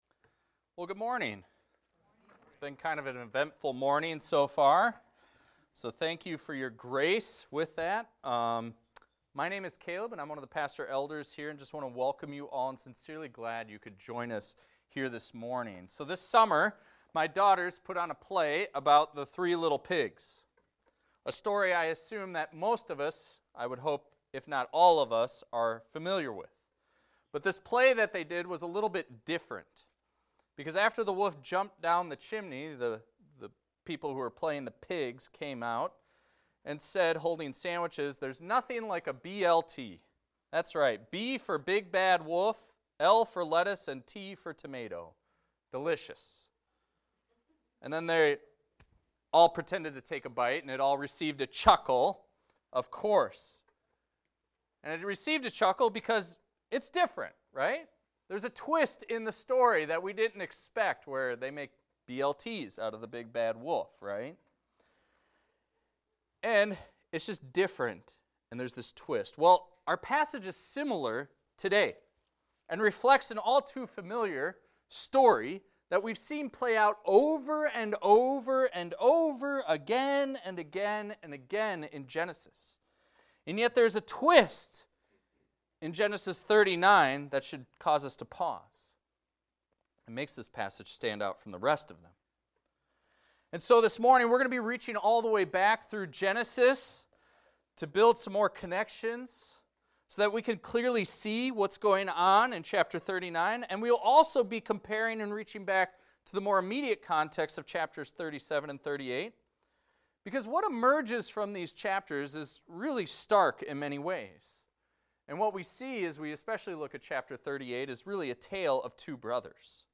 This is a recording of a sermon titled, "Breaking Cycles."